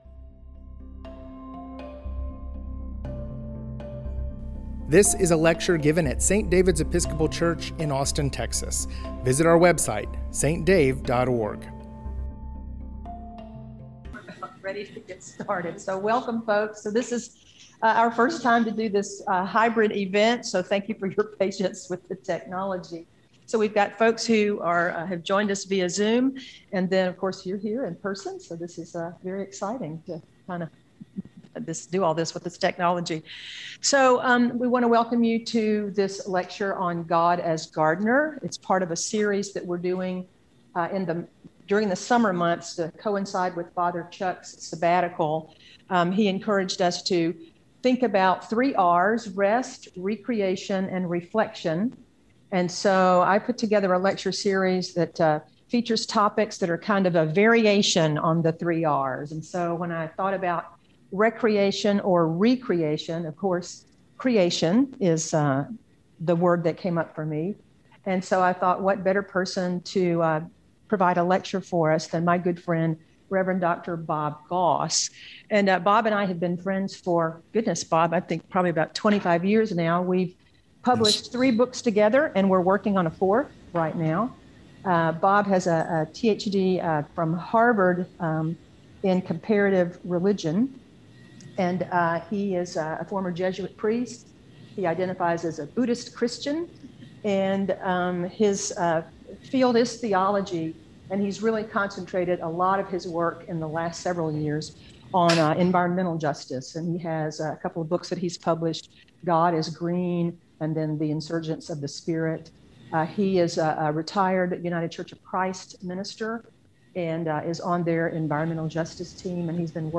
Summer Lecture Series: God as Gardener